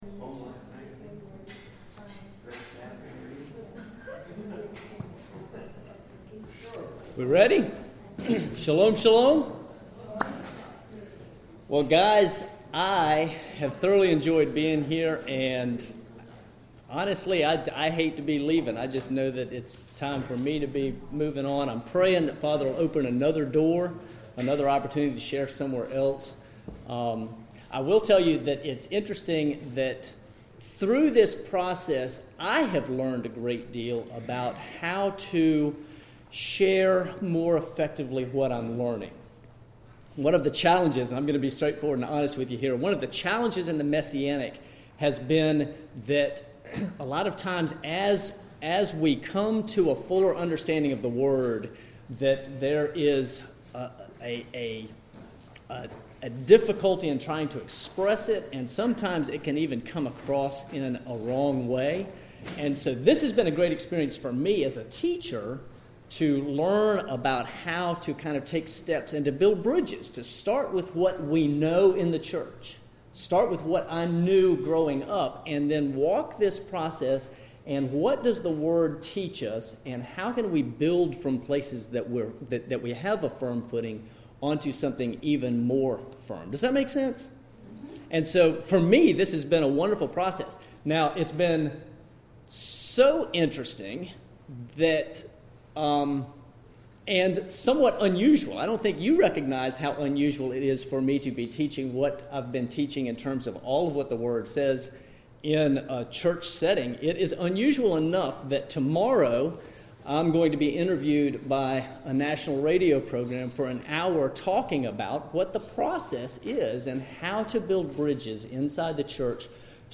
Final Sunday School Lesson: Under the Law
Here is the final lesson.